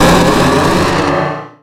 Cri de Méga-Dardargnan dans Pokémon Rubis Oméga et Saphir Alpha.
Cri_0015_Méga_ROSA.ogg